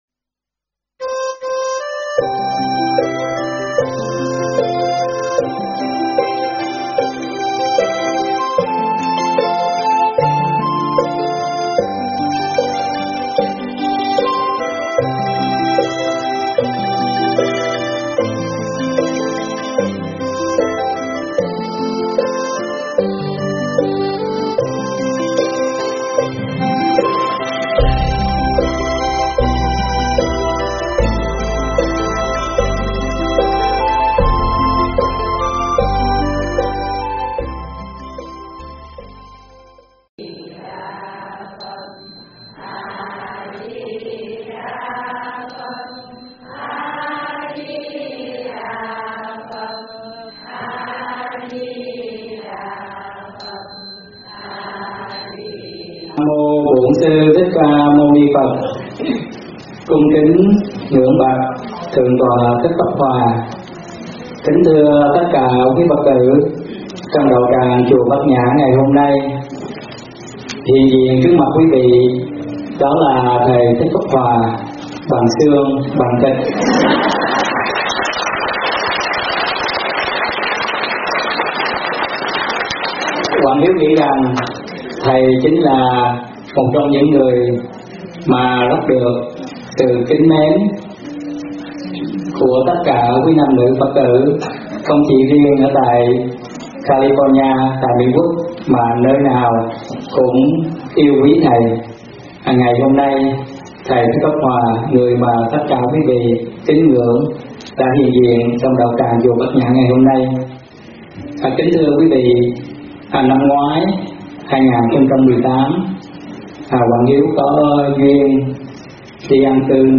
thuyết pháp Thân Tại Gia Tâm Xuất Gia
giảng tại Chùa Bát Nhã, California